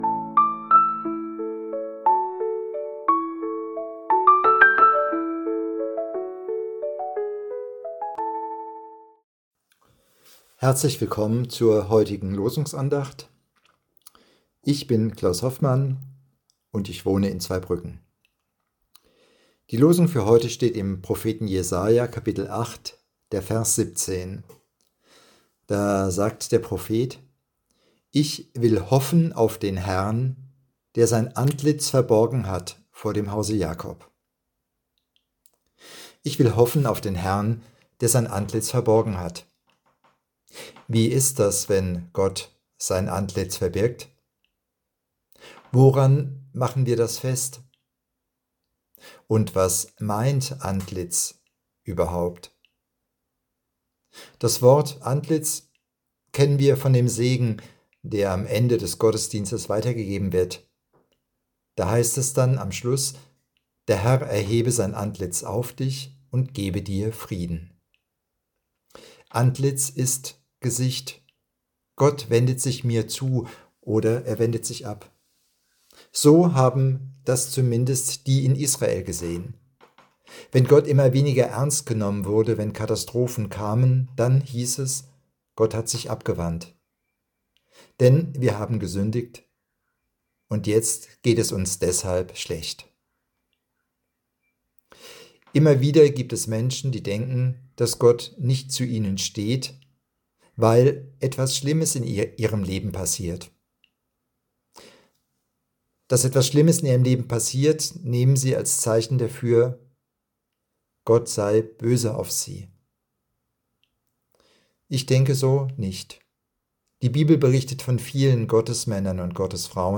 Losungsandacht für Freitag, 16.05.2025